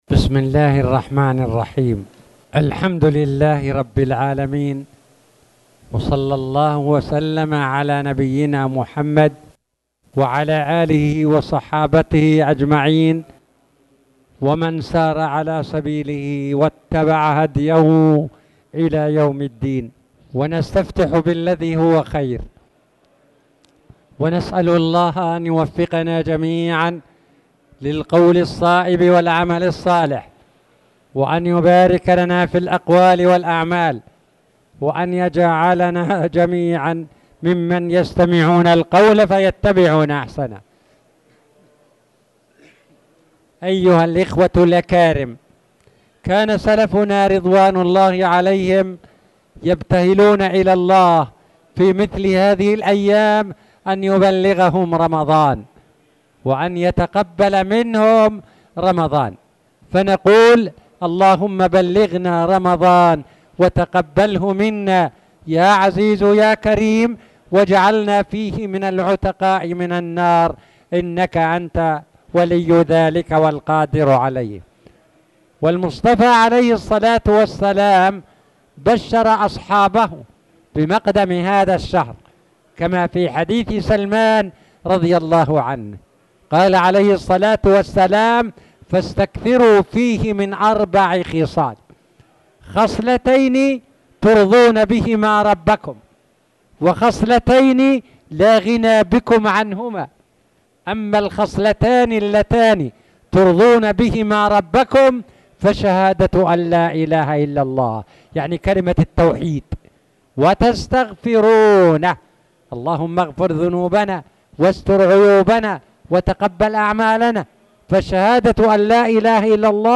تاريخ النشر ٢٦ شعبان ١٤٣٨ هـ المكان: المسجد الحرام الشيخ